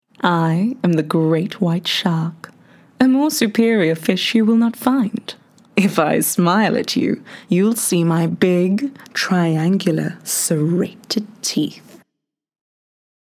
Seductive/thrilling
My neutral South African, British and American accents lend themselves to easy listening.